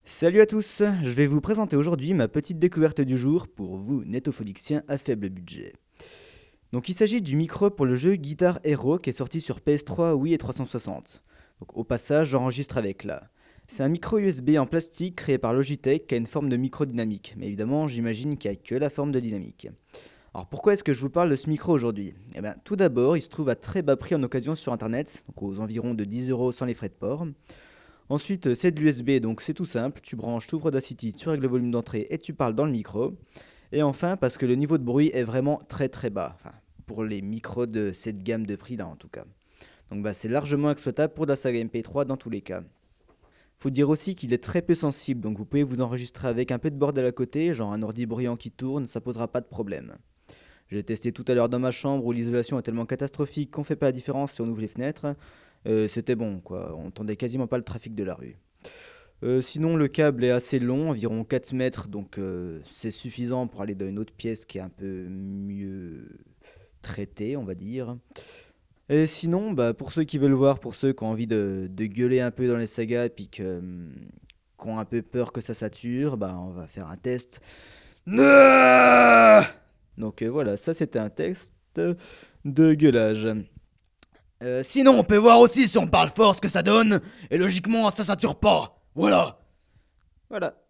Il est assez peu sensible, et a un niveau de bruit relativement bas (en tout cas, avec la dernière version d'Audacity, le bruit se vire très facilement, sans dommages audibles sur la voix).
C'est presque non traité : j'ai amplifié le passage parlé de 5-6dB, pour qu'il n'y ait pas trop de différence avec le passage crié.
Je tenais le micro à la main tout le long, donc non pas de bruit de manipulation si on le tient fermement. Par contre, j'ai utilisé un anti-pop, et enregistré dans ma salle traitée acoustiquement.
test_micro_guitar_hero.wav